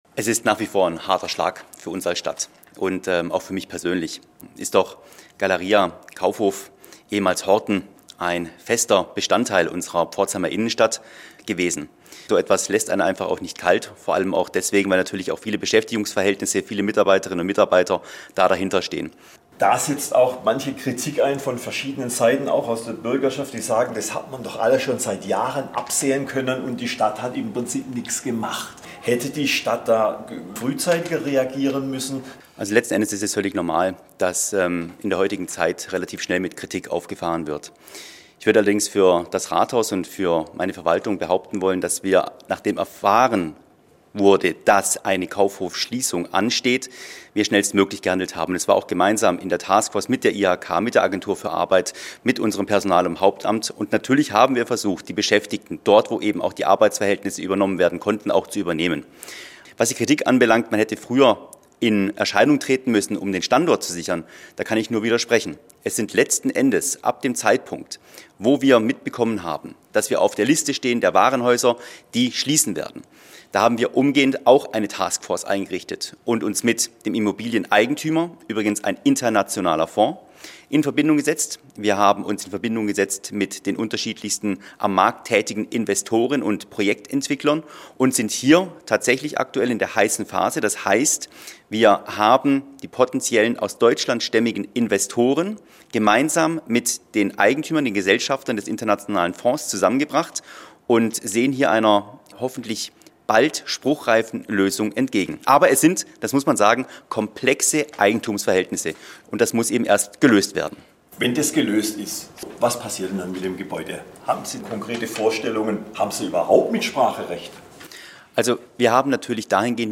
Im SWR-Interview stellte Oberbürgermeister Peter Boch (CDU) zunächst klar, dass das Thema im Rathaus höchste Priorität habe.